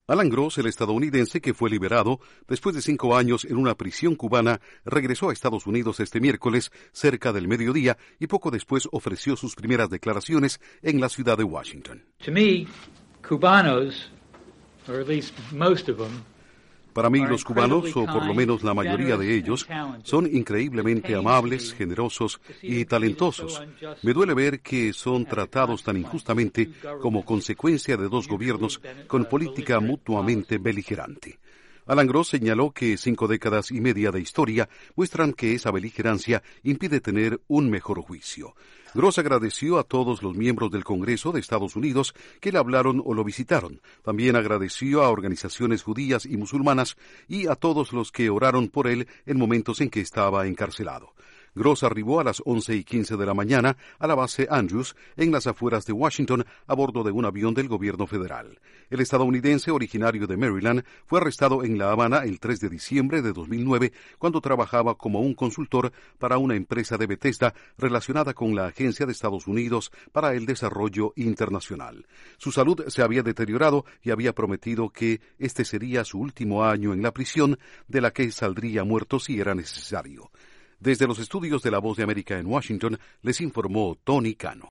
Alan Gross se dirige a la prensa en Estados Unidos pocas horas después de ser liberado de Cuba. Informa desde los estudios de la Voz de América en Washington